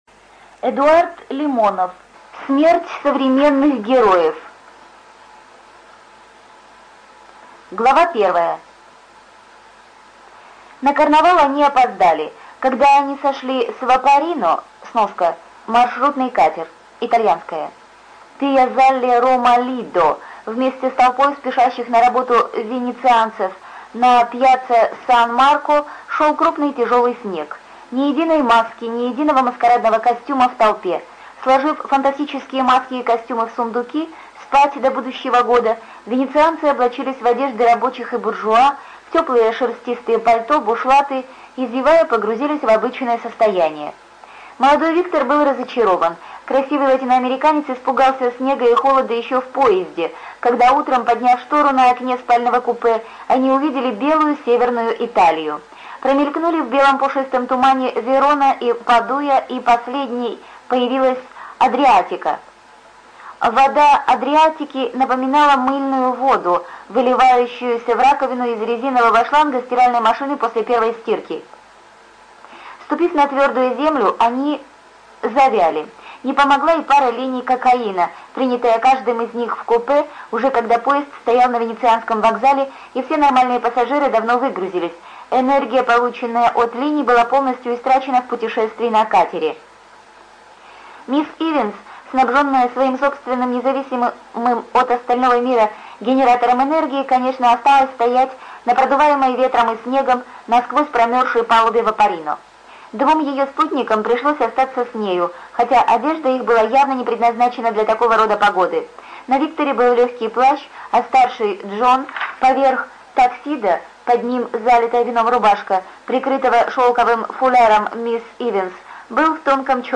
ЖанрСовременная проза
Студия звукозаписиТюменская областная библиотека для слепых